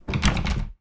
door sounds
blocked2.wav